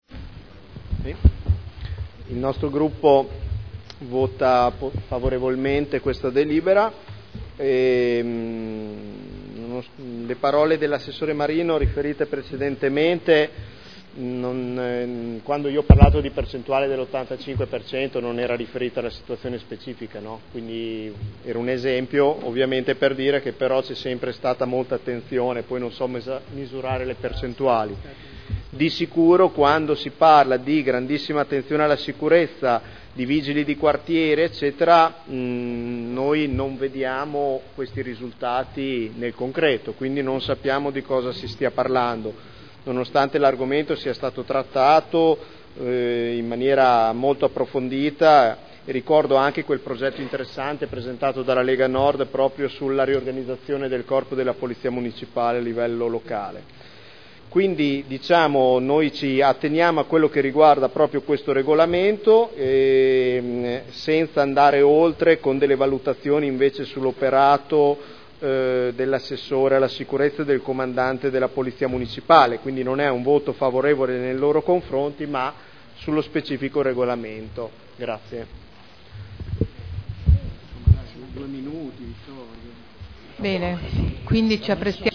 Seduta del 05/12/2011. Dichiarazione di voto.